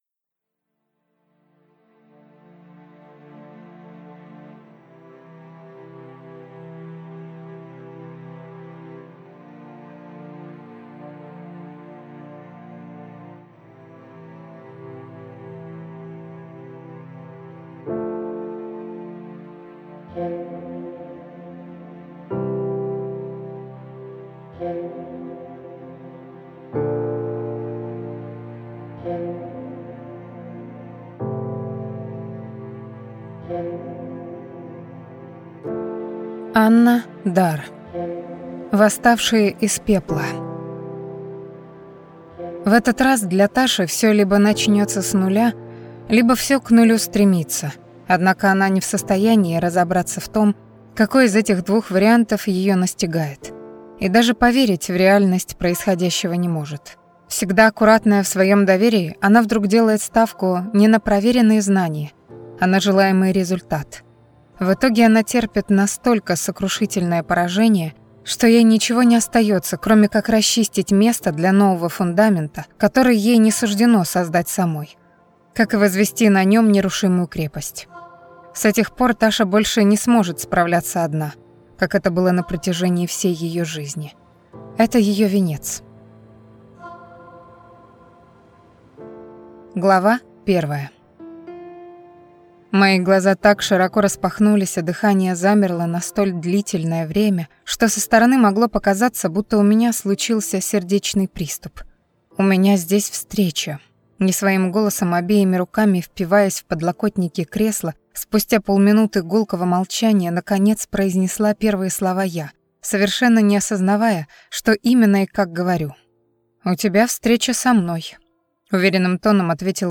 Аудиокнига Восставшие из пепла | Библиотека аудиокниг